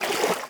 swim5.wav